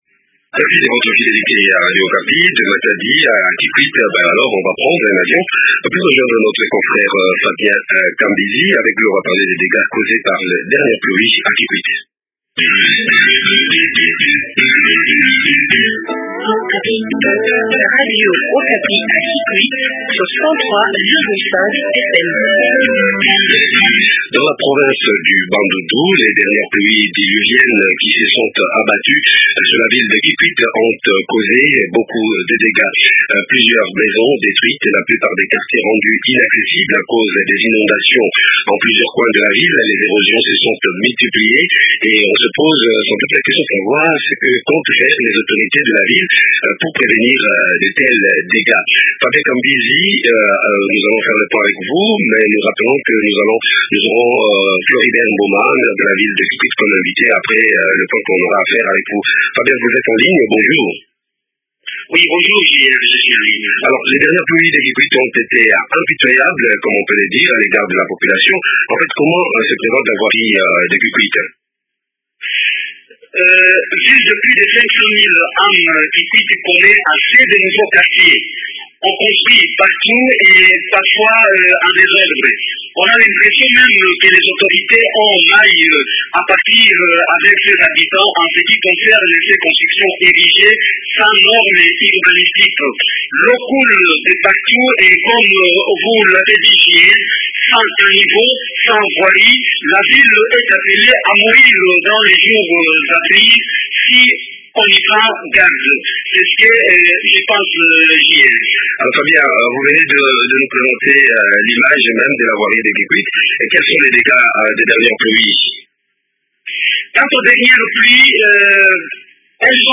Floribert Mboma, maire de la ville de Kikwit.